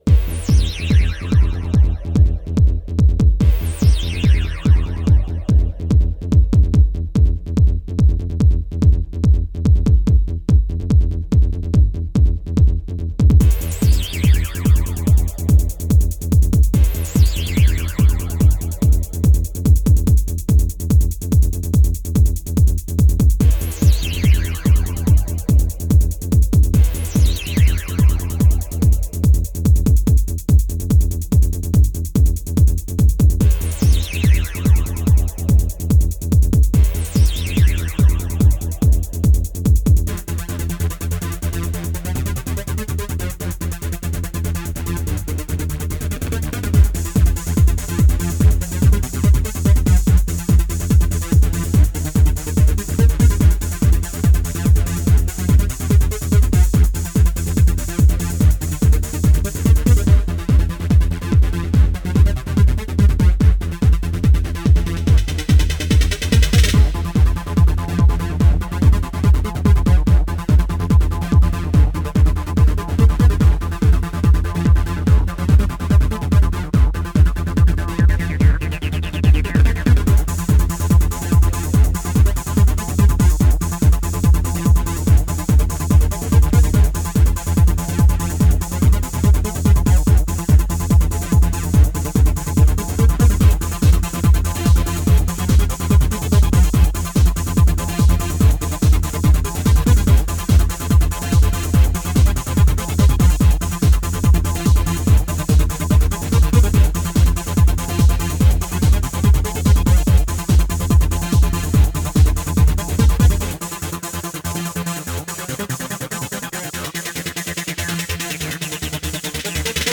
Файл в обменнике2 Myзыкa->Psy-trance, Full-on
Style: Psy-Trance, Goa